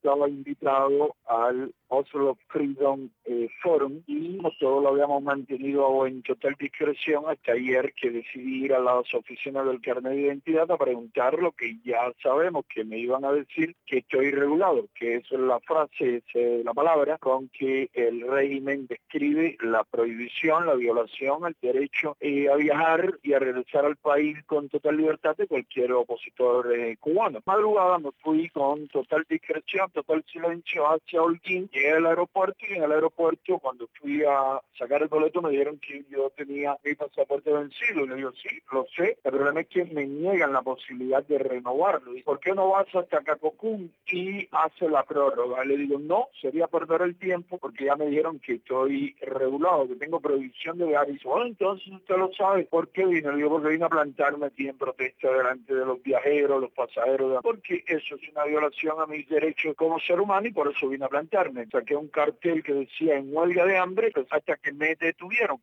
Declaraciones de José Daniel Ferrer a Radio Martí.